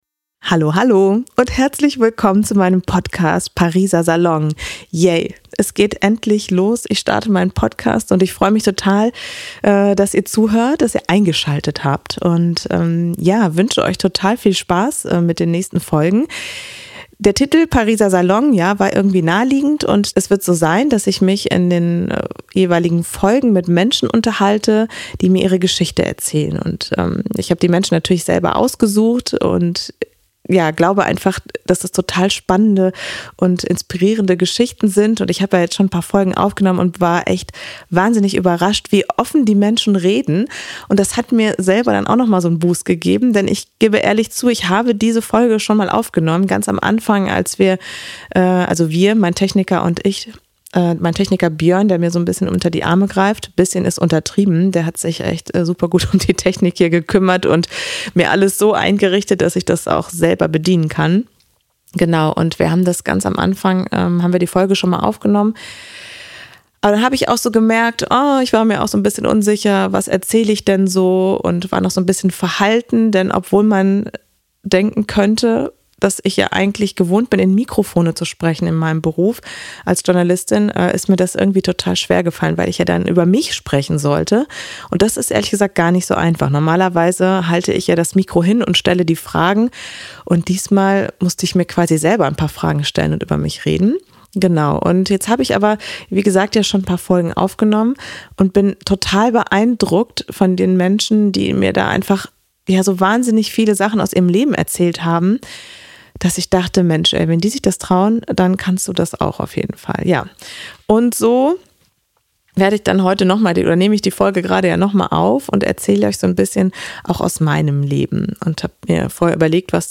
Solo-Folge erzähle ich euch ein bisschen darüber, was euch hier